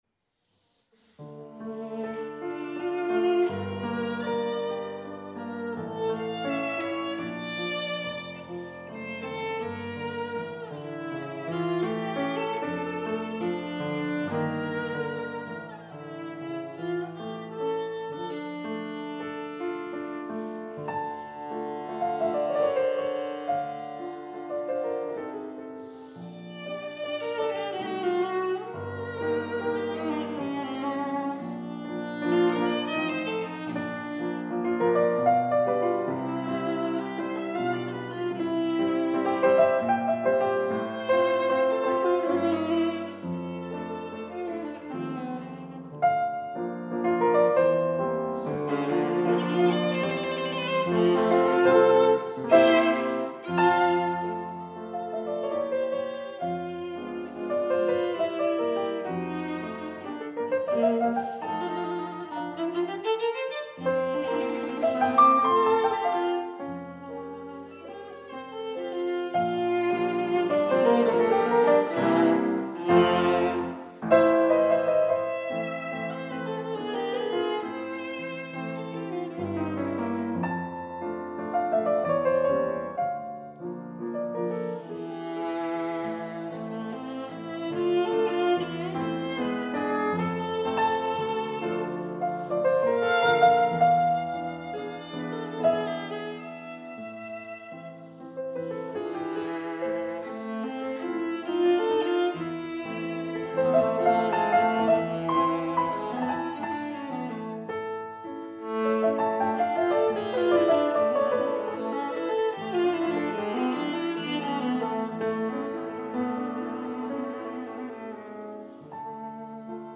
Vivez le charme d’un programme explorant la fantaisie et l’imaginaire au travers de transcriptions et d’œuvres variées pour alto. Du style romantique allemand de Schumannau charme français de la sonate de Franck, réimaginée pour alto et piano, en passant par des œuvres pour alto seul, ce programme met en vedette l’alto comme instrument virtuose et expressif, un des plus semblables à la voix humaine.
I. Nicht schnell
shumann_marchenbilder_1er-mouv.mp3